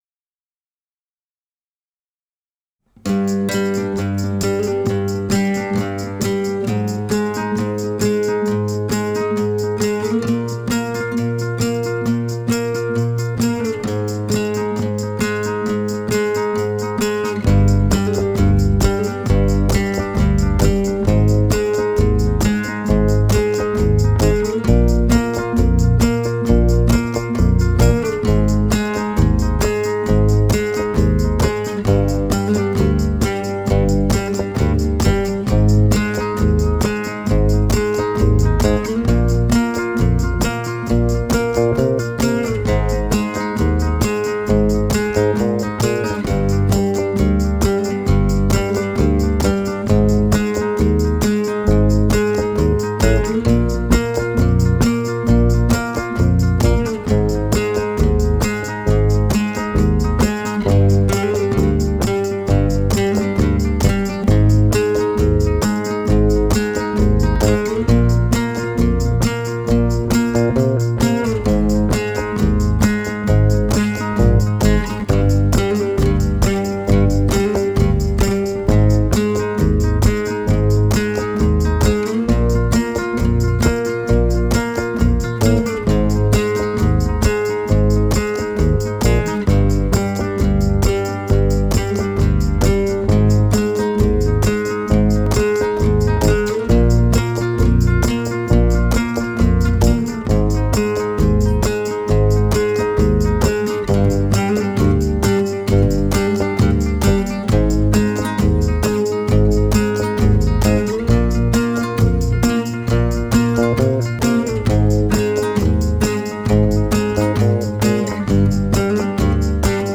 La Zambra vient des Maures, les mélodies sont arabisé.
La Zambra s'est niché du côté de Granada dans les grottes del Sacromonte elle était joué dans les rituels de mariages Gitans, ce qui lui donne une sonorité arabo-andalouse.